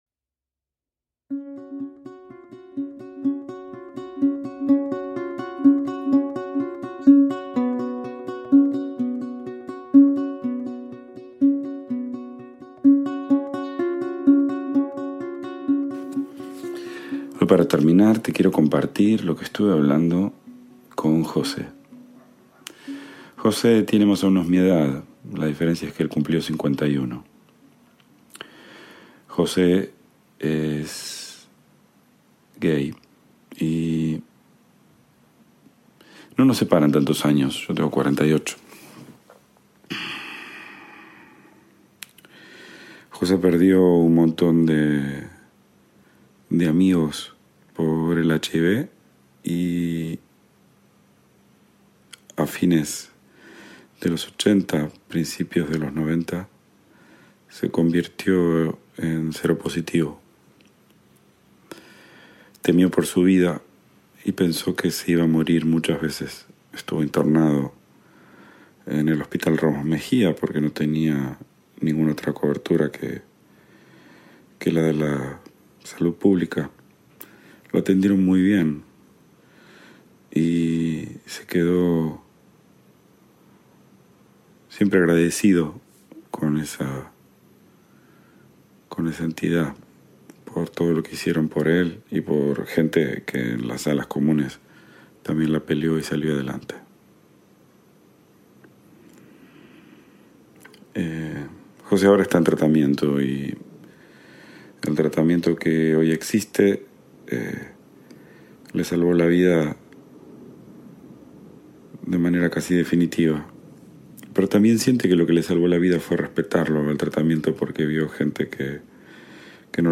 IMPORTANTE: Esta serie fue grabada durante una época de muchos viajes, directamente en mi teléfono móvil. La calidad del audio no está a la altura de lo que escucharás en las siguientes series y episodios.